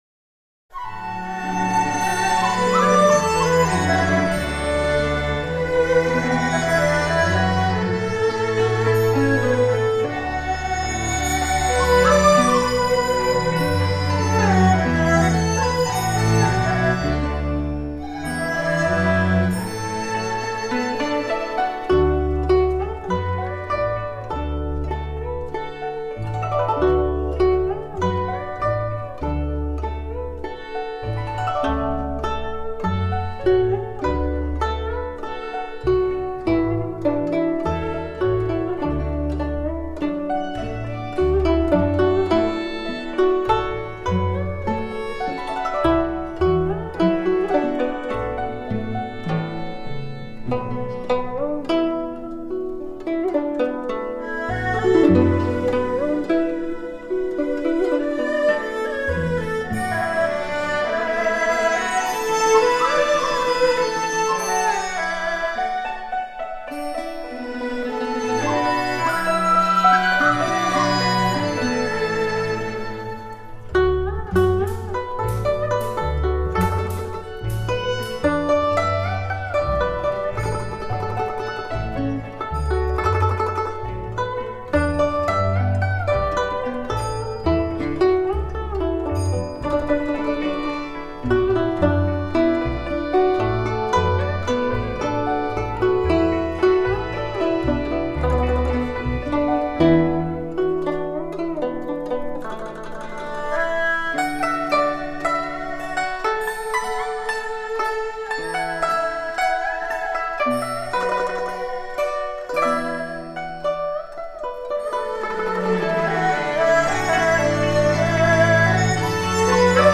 演绎细致严谨，颇为传神
这是由古筝与乐团，古筝与二胡，双古筝与二胡，电子乐器及古筝独奏集合而成的综合乐曲